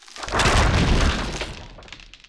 icefall2.wav